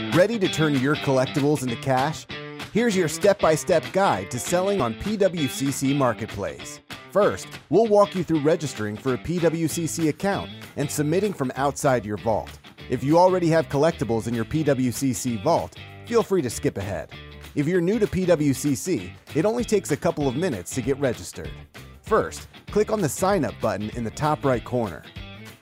Male
-Neumann TLM 103 Condensor Microphone
E-Learning
Words that describe my voice are real, conversational, youthful.